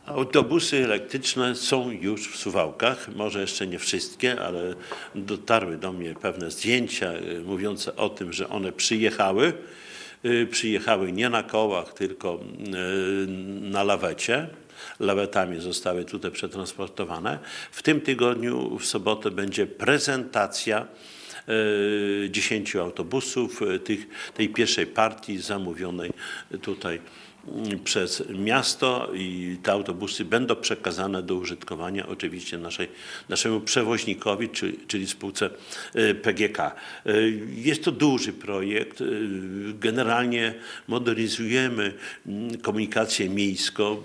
O szczegółach mówił w Audycji z Ratusza Czesław Renkiewicz, prezydent Suwałk.